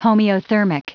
Prononciation du mot homeothermic en anglais (fichier audio)
Prononciation du mot : homeothermic